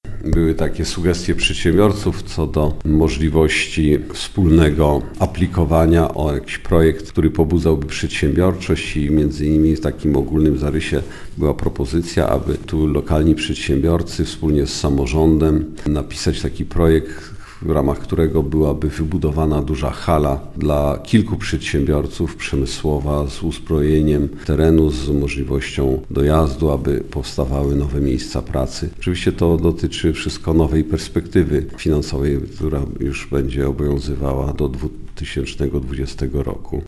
O tym jak pobudzić lokalną przedsiębiorczość dyskutowano na spotkaniu przedstawicieli miasta i biznesu. Mówi burmistrz Krasnegostawu Andrzej Jakubiec.